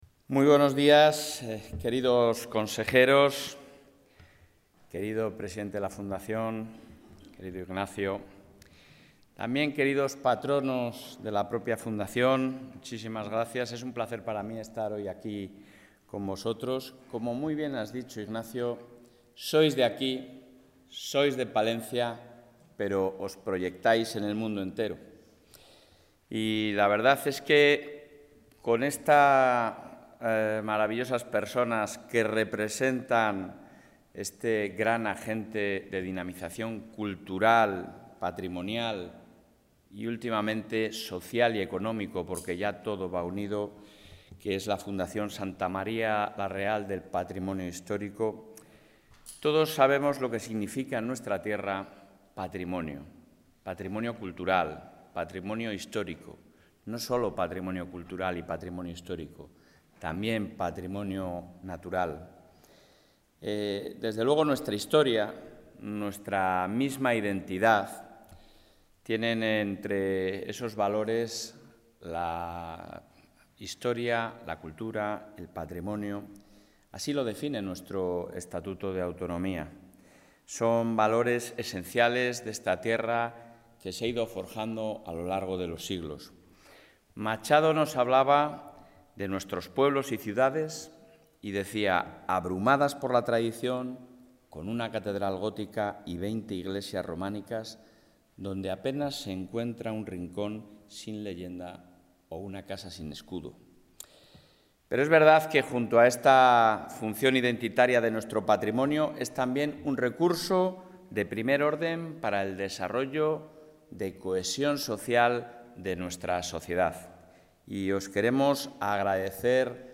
Intervención del presidente de la Junta.
En un encuentro mantenido hoy en la Sede de la Presidencia de la Junta con los representantes de la Fundación Santa María la Real, el presidente del Gobierno autonómico, Alfonso Fernández Mañueco, ha puesto en valor el patrimonio cultural de Castilla y León por ser un recurso esencial para su desarrollo económico y, también, una importante herramienta de cohesión social.